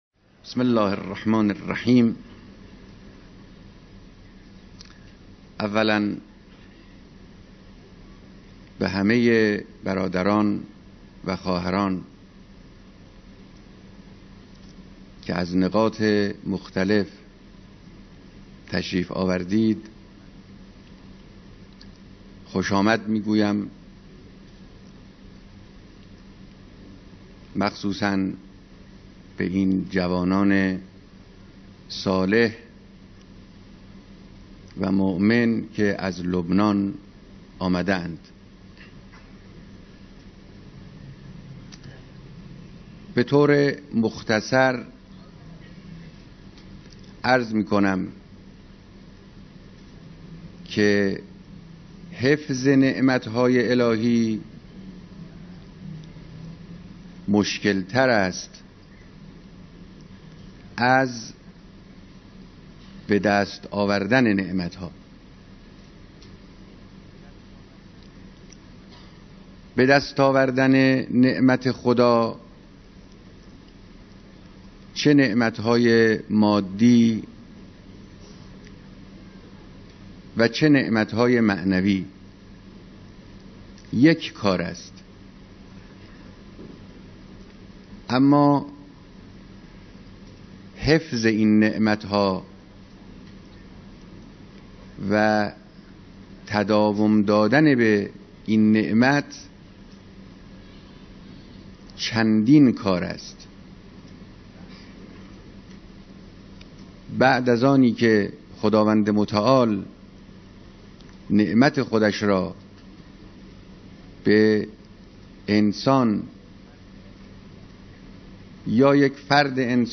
بیانات رهبر انقلاب در دیدار اقشار مختلف مردم